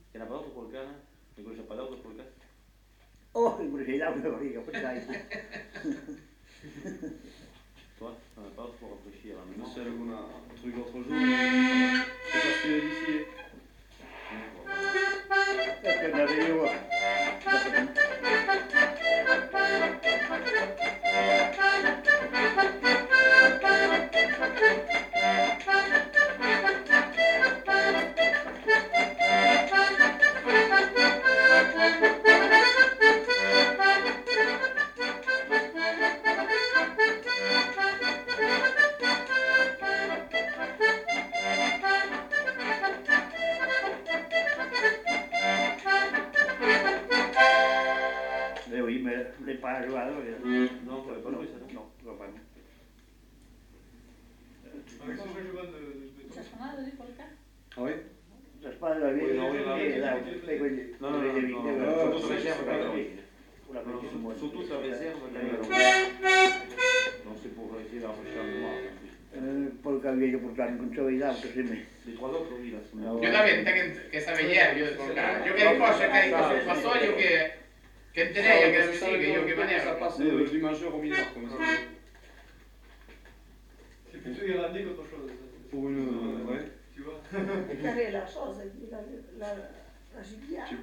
Lieu : Lencouacq
Genre : morceau instrumental
Instrument de musique : accordéon diatonique
Danse : polka